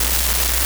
Eso no es estática. Es ruido de Barkhausen.
La textura es granular. Frágil. Suena como metal bajo demasiada tensión.